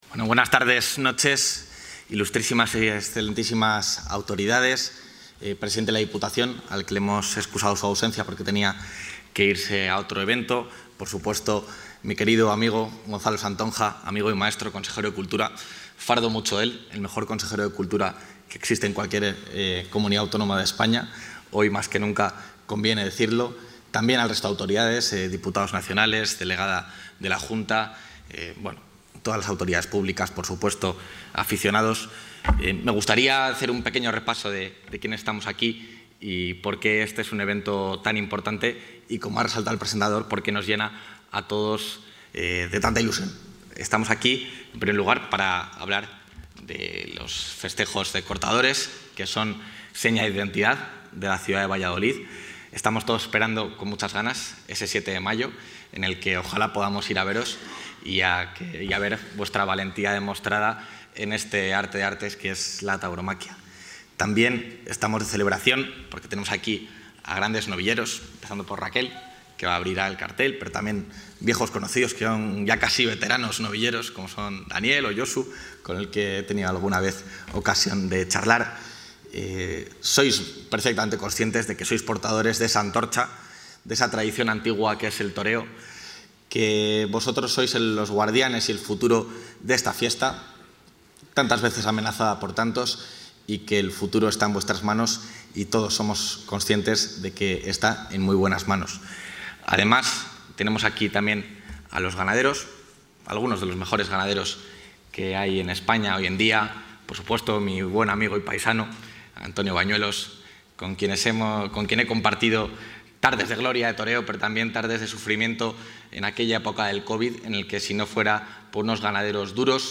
El vicepresidente de la Junta, Juan García-Gallardo, ha clausurado durante la noche de hoy en el Teatro Zorrilla el acto de...
Intervención del vicepresidente.